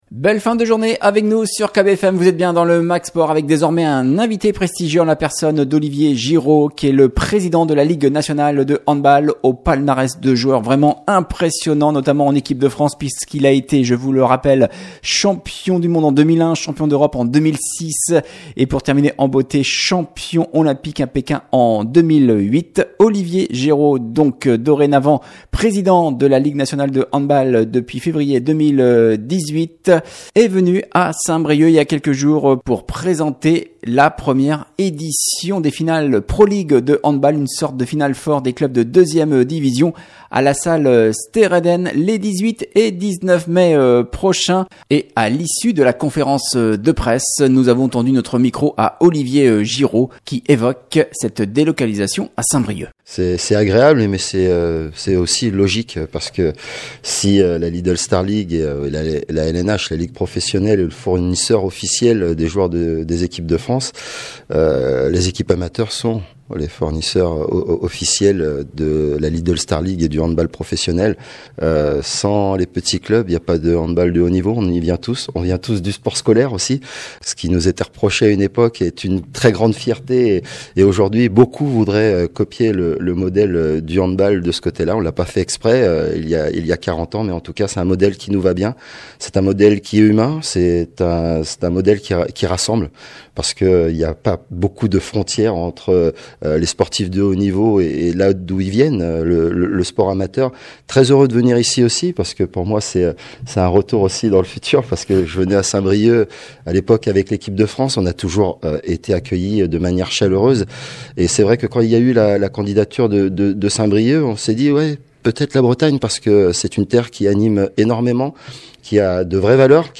Entretien avec Olivier Girault, président de la Ligue Nationale de Handball